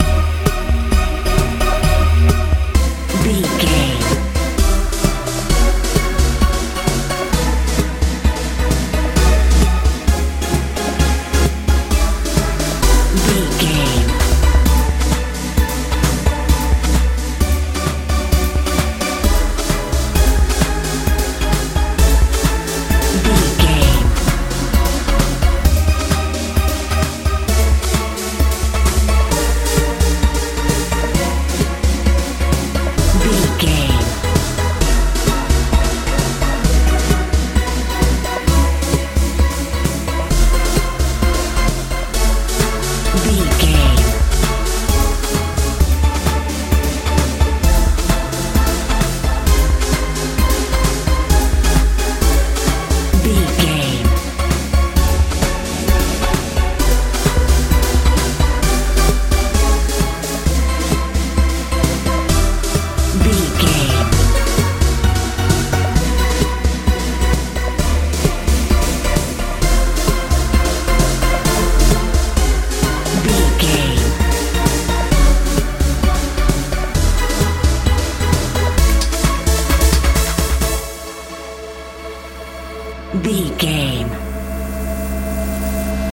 house
Ionian/Major
E♭
confused
frantic
synthesiser
bass guitar
drums
80s
suspense
strange
tension